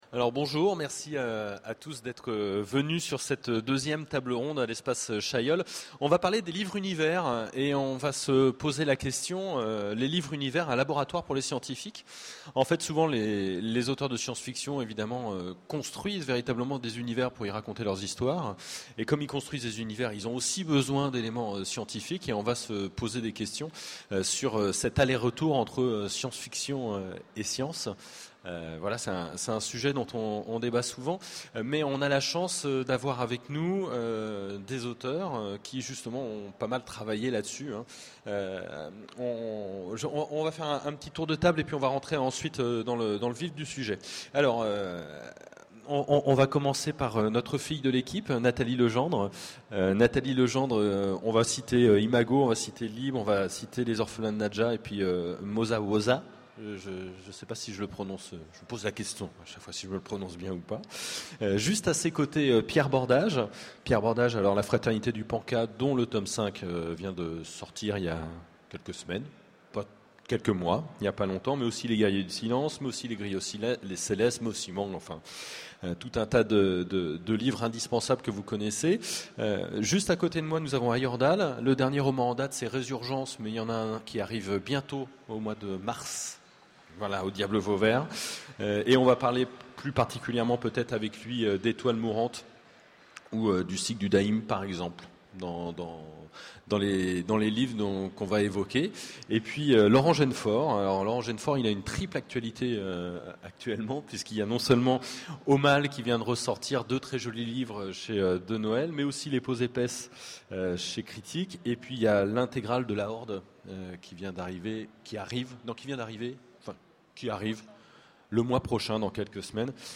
Utopiales 12 : Conférence Les livres-univers